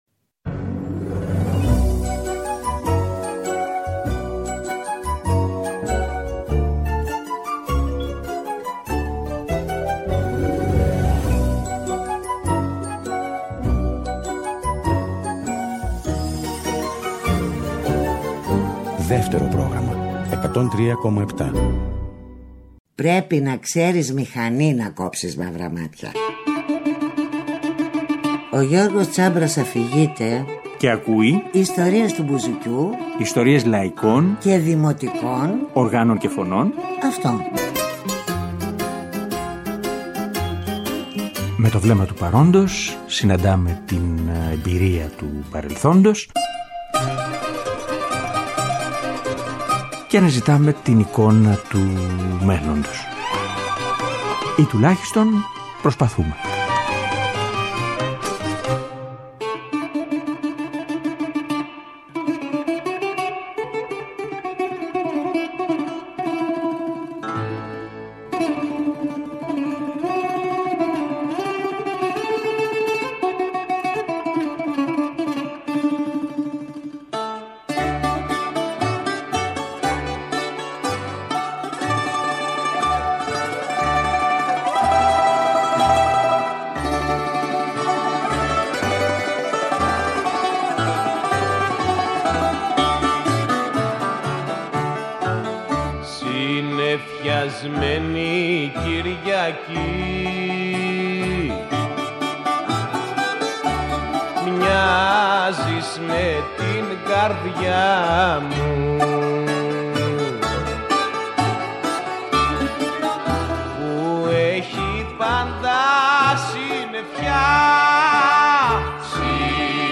ταξίμι